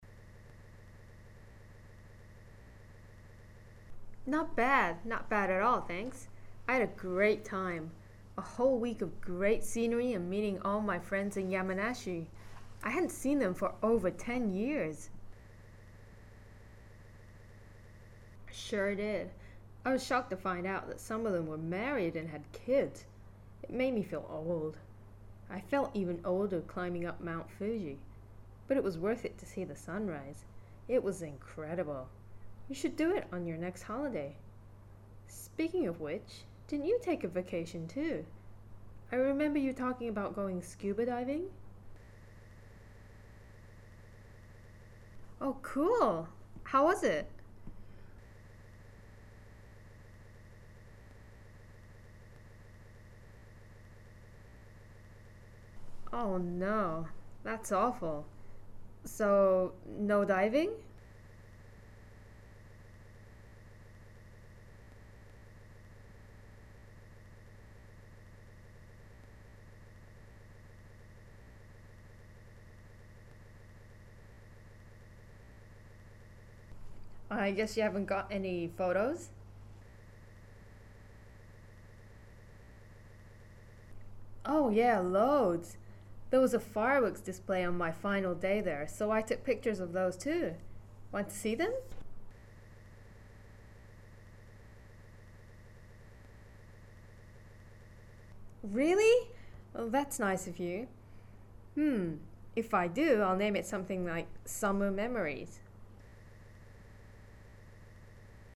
Mizuho役をしてくれたネイティブスピーカーはItをあえて弱く発音しています。